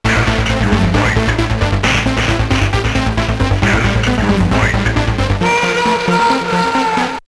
Ten sam oryginalny fragment utworu został zpróbkowany z komputera PC samplerem produkcji BIW.
Następnie odtworzone fragmenty z pamięci RAM commodore zostały zsamplowane przez kartę dźwiękową komputera PC i zapisane w formacie 22kHz 16 bitów mono.
mortal-4bit-covox-70.wav